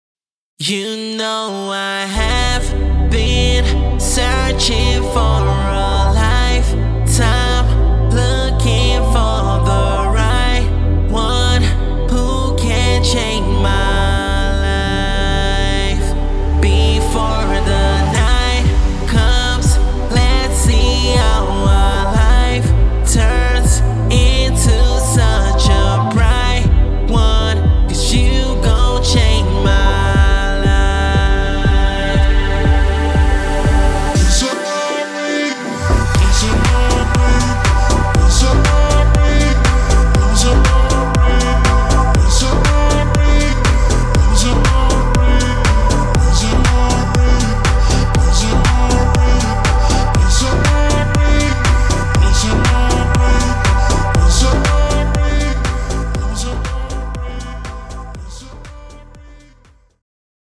a collection of powerful vocals